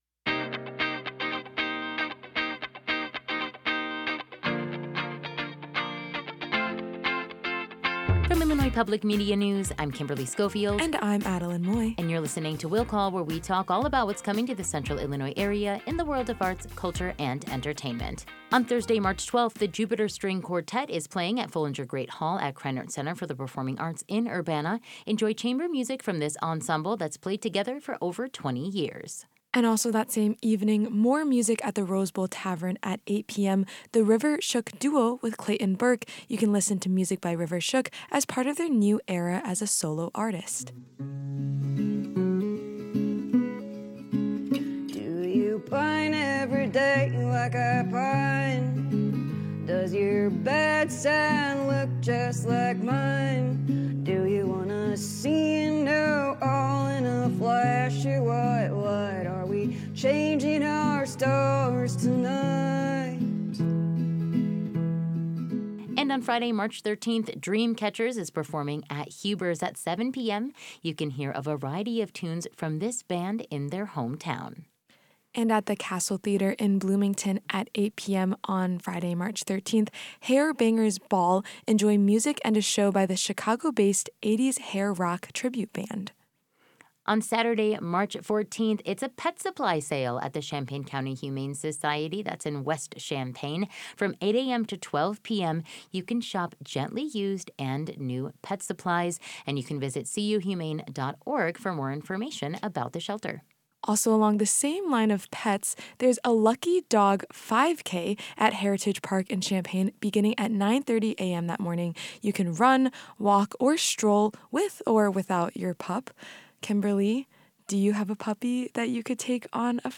talk about weekend events